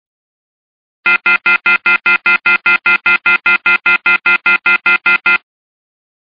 busy-telephone_24876.mp3